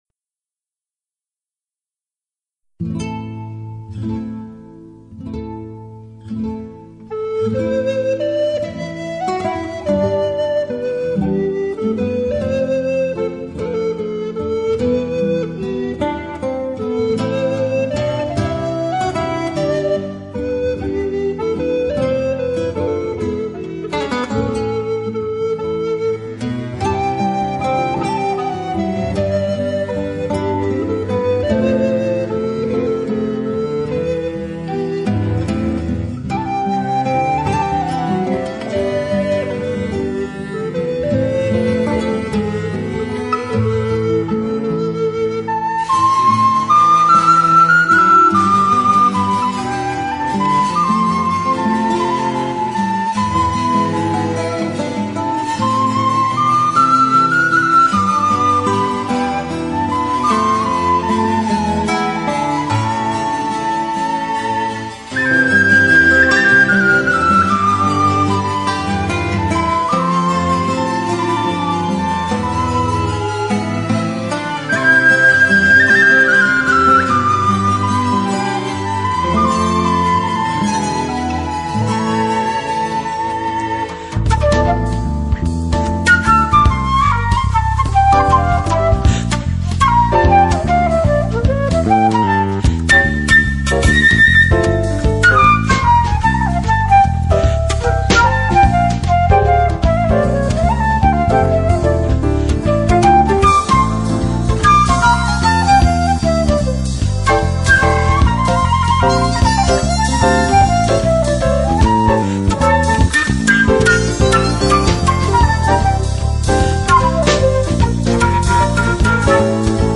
精心以現代流行編曲手法 與古典結合，模造出一張可聽性極高的CD，從而改變牧童笛在音樂上的框框。